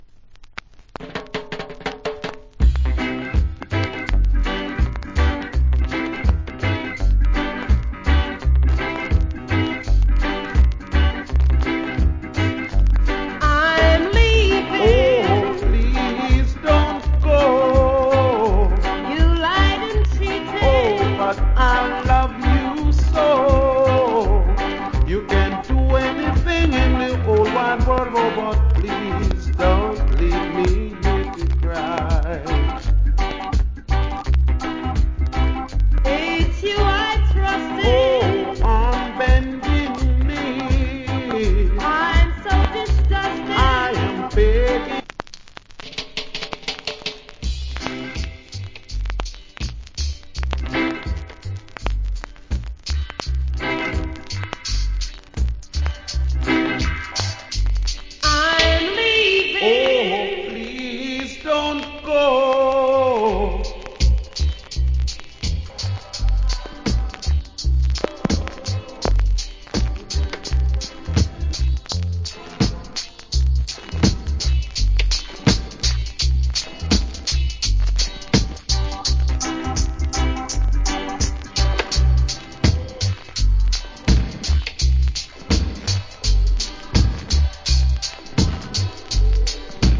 Good Duet Reggae Vocal.